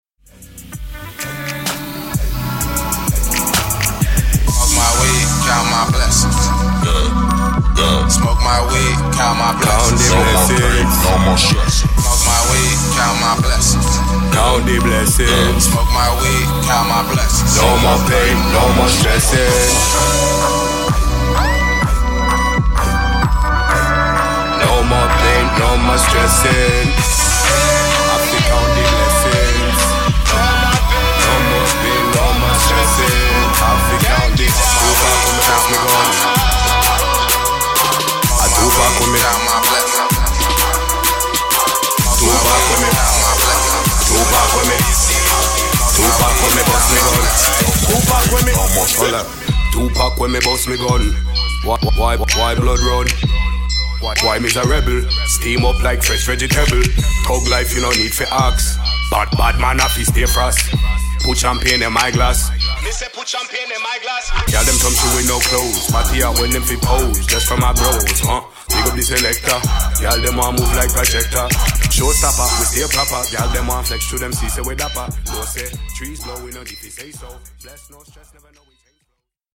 Hip HopTrap
带有键和速度标记的循环，包括一击和人声。
• 1070 Vocals (incl. 26 Full Acapellas)
• 323 Melodic Loops
• 123 Drum Loops